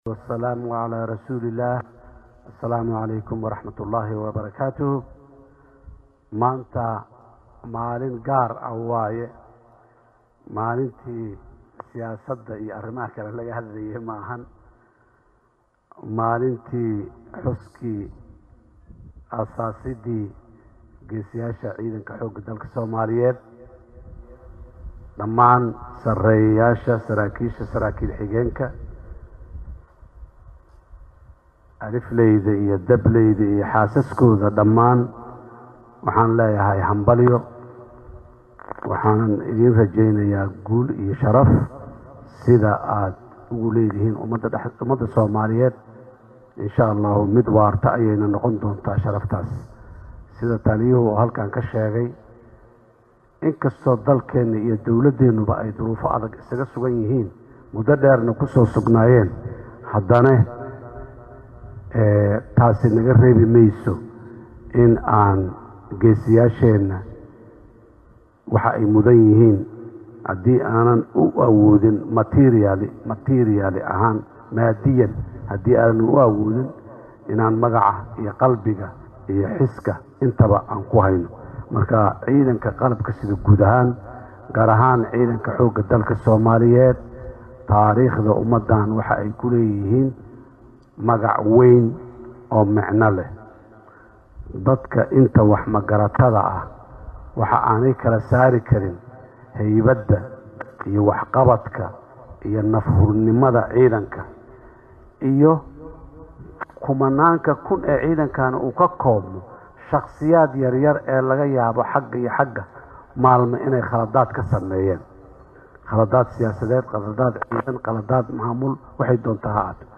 Madaxweynaha Jamhuuriyadda Federaalka Soomaaliya Xasan Sheekh Maxamuud ayaa waxaa uu hambalyo iyo bogaadin u diray dhammaan saraakiisha ka tirsan Ciidanka Xooga Dalka Soomaaliyeed xilli uu maanta khudbad ka jeediyay munnaasabadda sanadguurada 66-aad ee aas-aaska Ciidanka Xooga Dalka Soomaaliyeed.